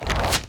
Rock Meteor Throw 1.wav